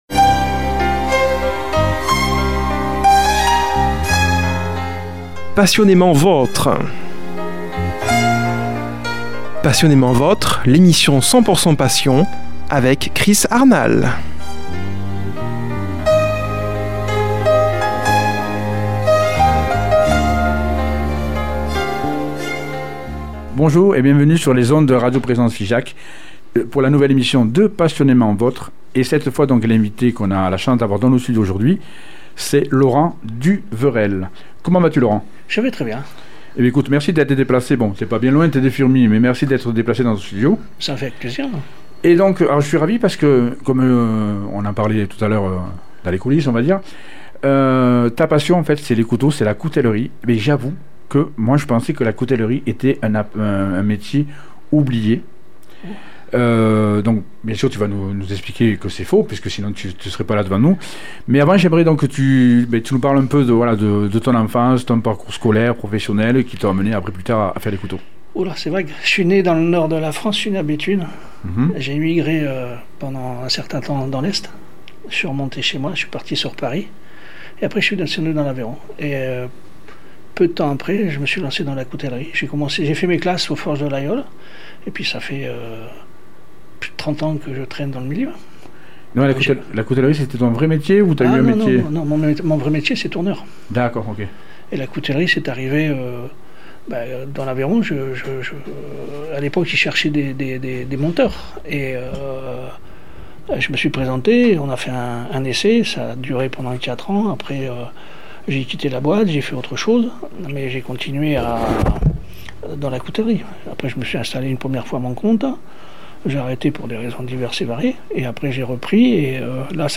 reçoit au studio comme invité